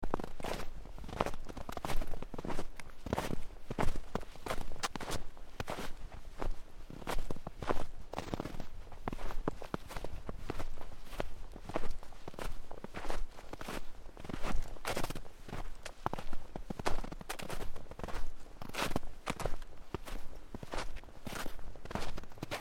Звуки шагов по снегу
хруст снега под шагами